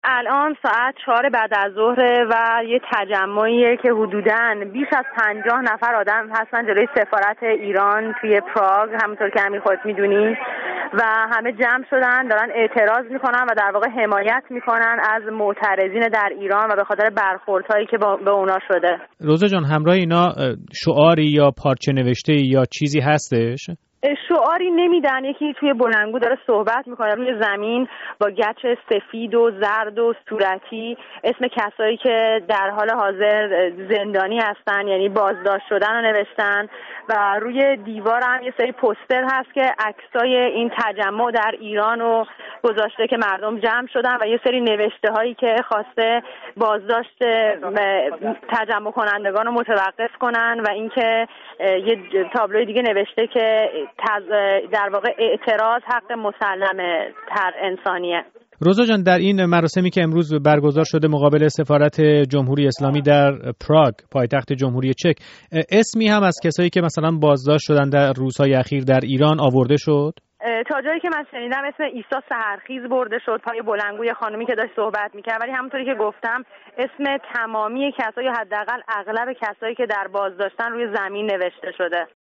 گزارش «روز جهانی اقدام» در پراگ